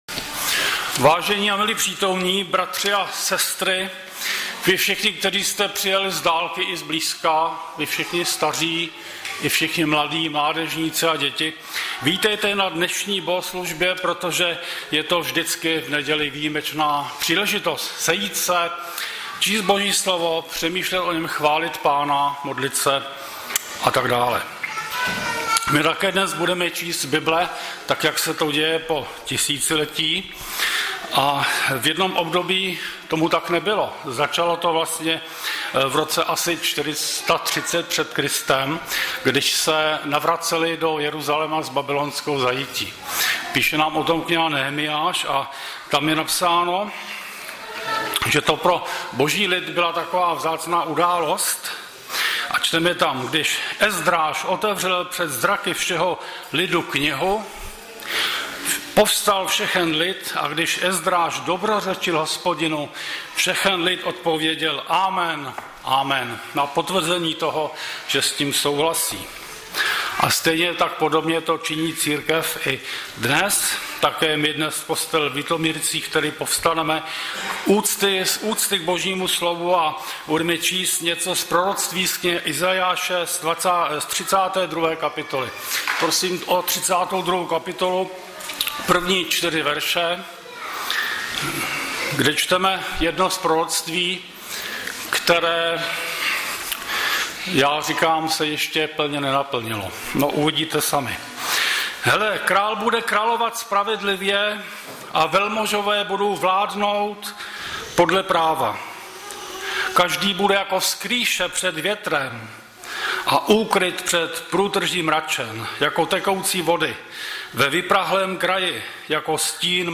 Audiozáznam kázání